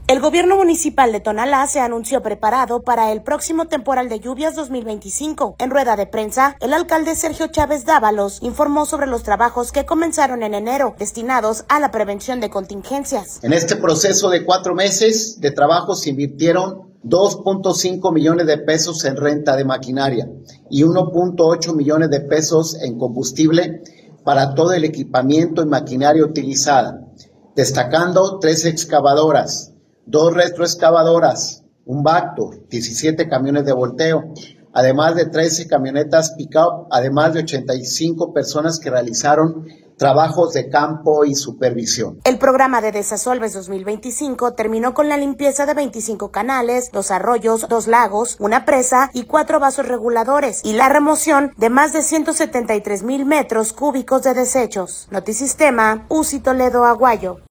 El Gobierno Municipal de Tonalá se anunció preparado para el próximo temporal de lluvias 2025. En rueda de prensa, el alcalde Sergio Chávez Dávalos informó sobre los trabajos que comenzaron en enero destinados a la prevención de contingencias.